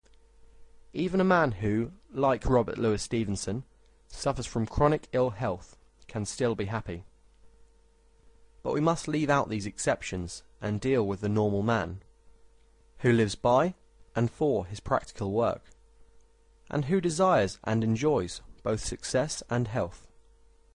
Lord Beaverbrooks Success Audiobook RR MRR
Please Note. All audiobooks that I offer are read by real people.
None of the junk read by a machine!